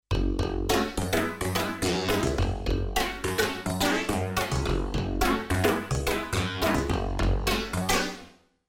Yamaha DX7 Soundbanks
A great and unique "revival" soundbank including typical but original digital FM synthesis patches - Click here to read a detailed patches description
IMPORTANT NOTE: slight external reverb and chorus FXs have been added for the MP3 demos